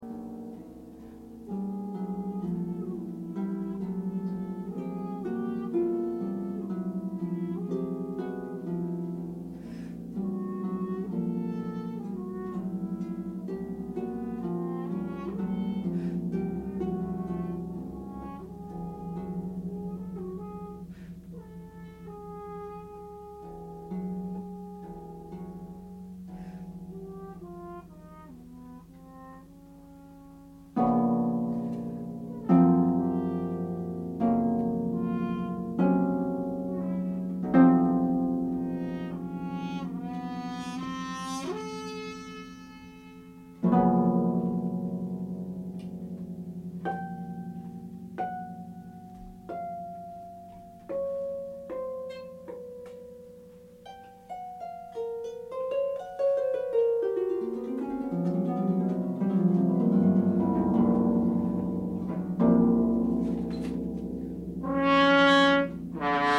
Repertoire Originally for Horn and Harp